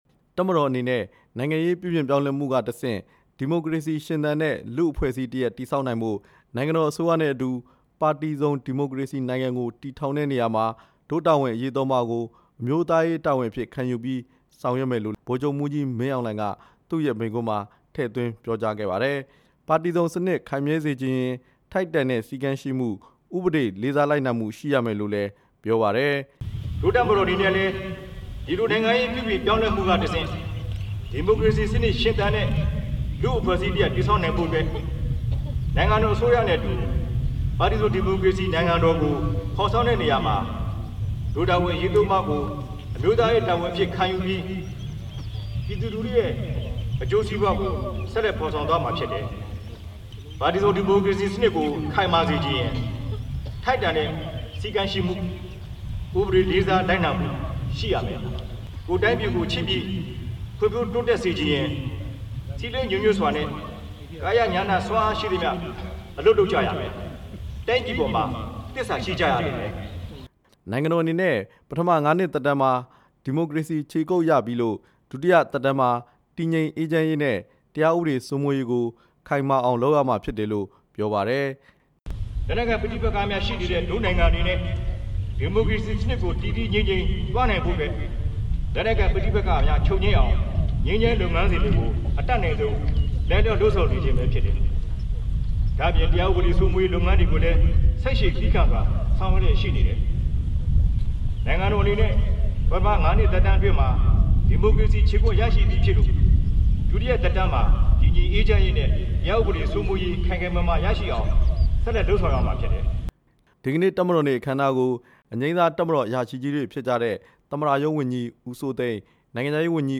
ဒီကနေ့ နေပြည်တော် တပ်မတော်စစ်ရေးပြကွင်းမှာကျင်းပတဲ့ ၇၁ နှစ်မြောက် တပ်မတော်နေ့ အခမ်းအနား မိန့်ခွန်း ပြောကြားရာမှာ ဗိုလ်ချုပ်မှူးကြီး မင်းအောင်လှိုင်က ထည့်သွင်းပြောကြား လိုက်တာဖြစ်ပါတယ်။